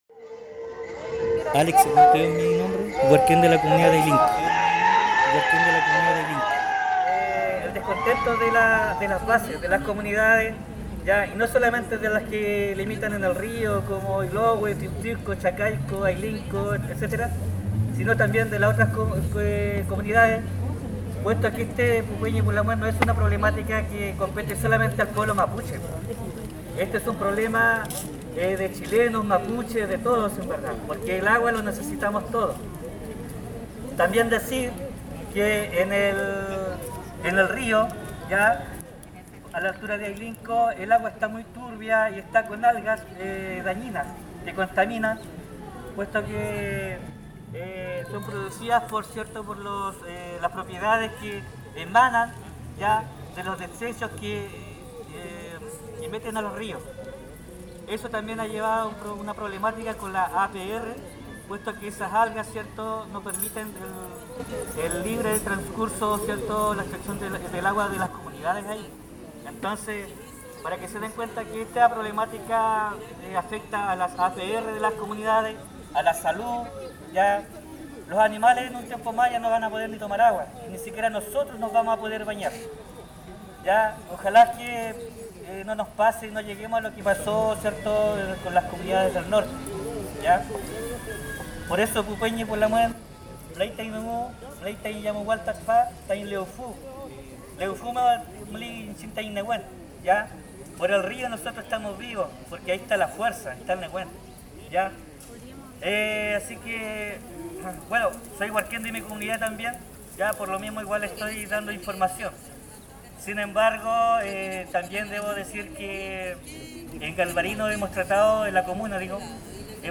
En esta actividad hubo varios oradores incluyendo los de la organizacion convocante denominada Ynkayaiñ Chol Chol Lewfu.